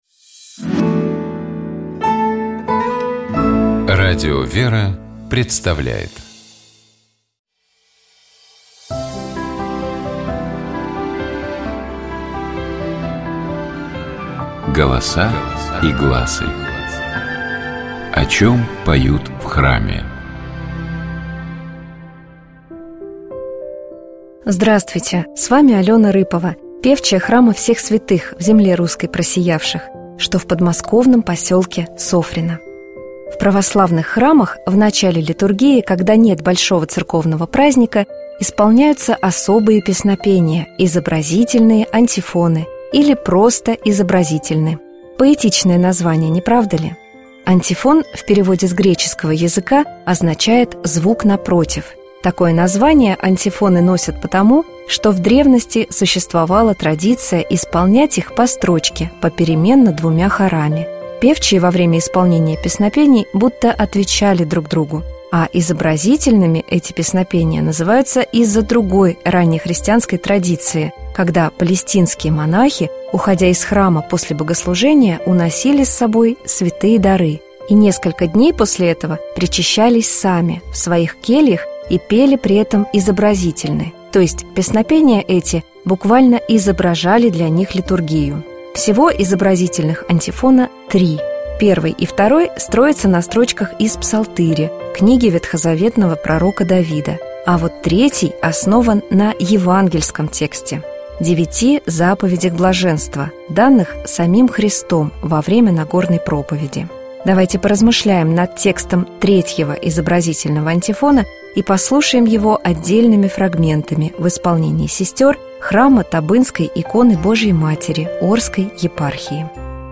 Давайте поразмышляем над текстом третьего изобразительного антифона и послушаем его отдельными фрагментами в исполнении сестёр храма Табынской иконы Божией Матери Орской епархии.
Послушаем первую часть антифона на церковнославянском языке: